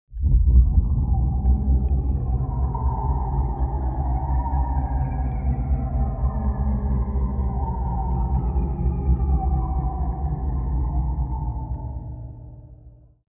دانلود آهنگ شب 8 از افکت صوتی طبیعت و محیط
دانلود صدای شب 8 از ساعد نیوز با لینک مستقیم و کیفیت بالا
جلوه های صوتی